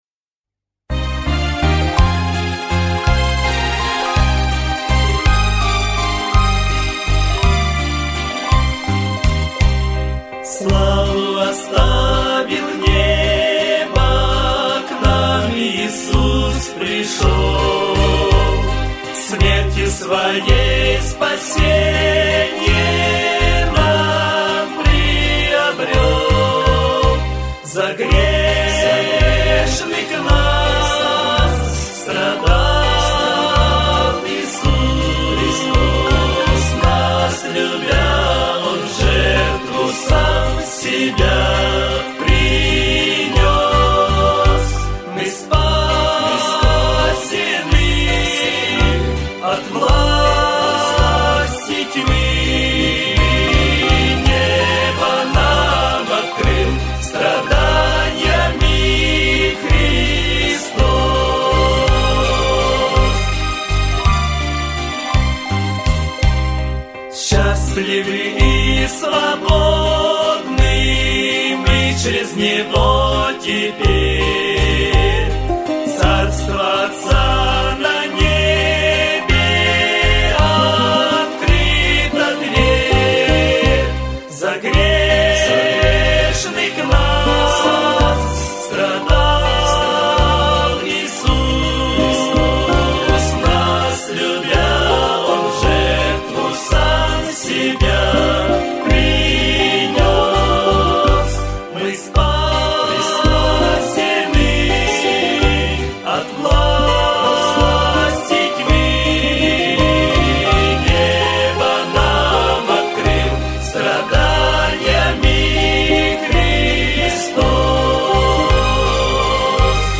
472 просмотра 704 прослушивания 105 скачиваний BPM: 77